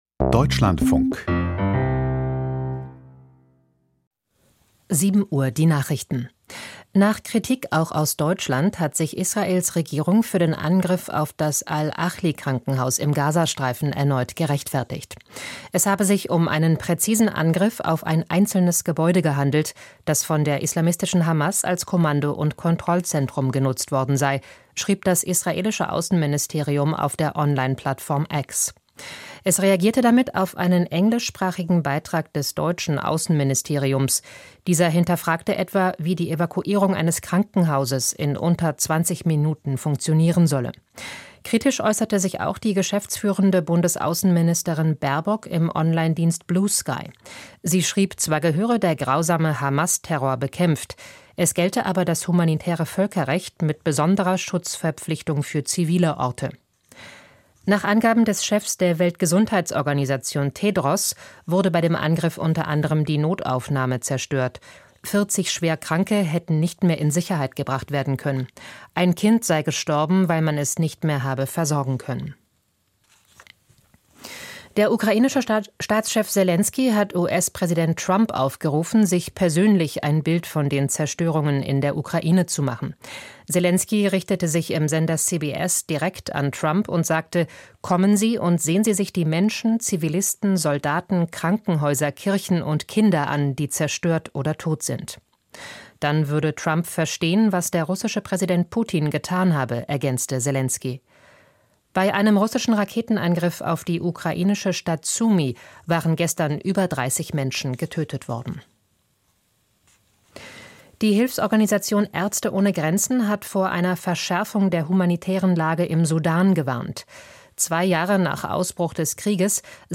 Die Deutschlandfunk-Nachrichten vom 14.04.2025, 07:00 Uhr